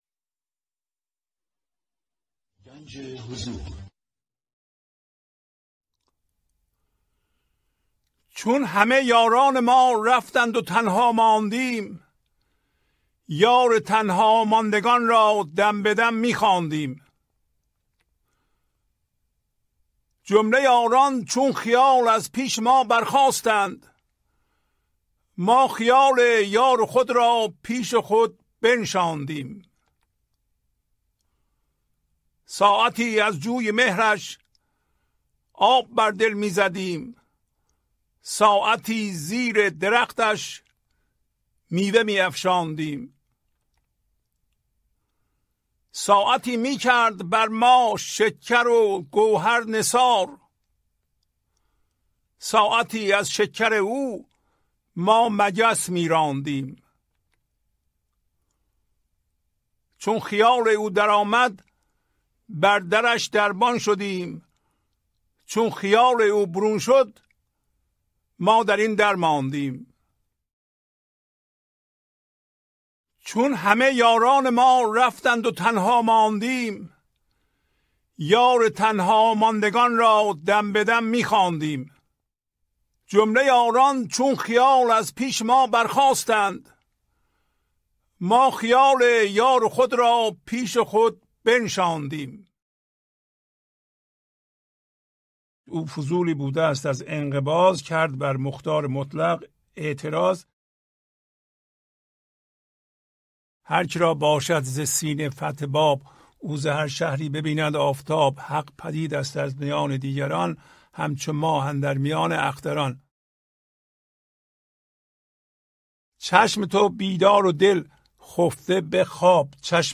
خوانش تمام ابیات این برنامه - فایل صوتی
1045-Poems-Voice.mp3